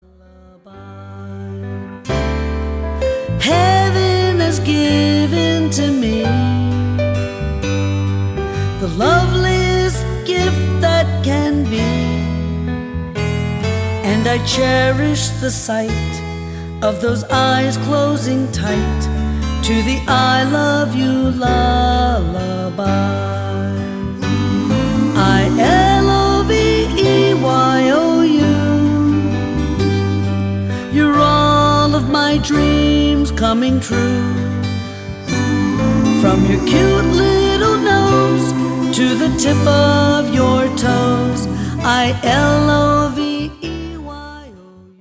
Upbeat songs about Values for the whole family.